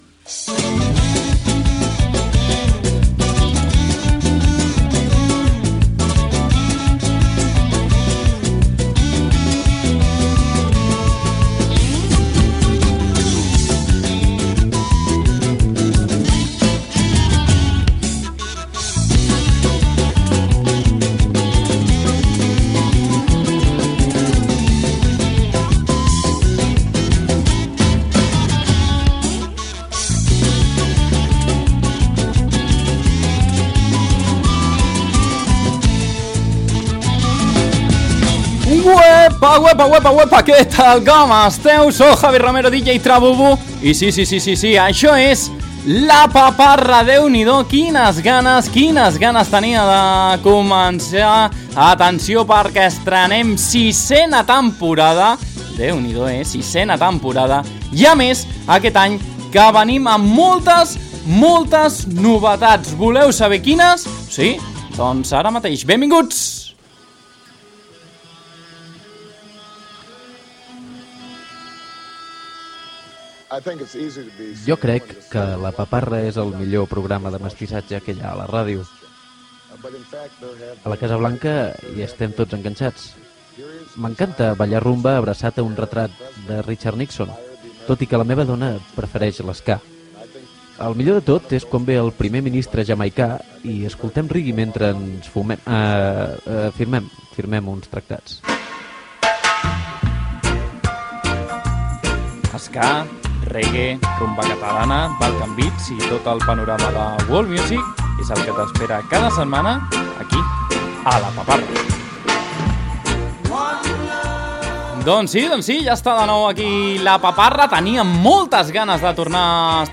Sintonia i presentació del primer programa de la sisena temporada, estils musicals, salutació a les noves emissores que emeten el programa, com Ràdio Canovelles, xarxes socials del programa
Musical
FM